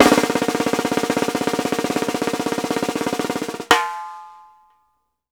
FFROLL +AC-R.wav